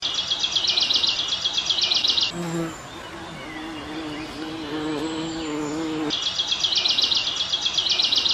Waldgeräusch 3: Hummel und Waldvögel / forest sound 3: bumblebee and forest birds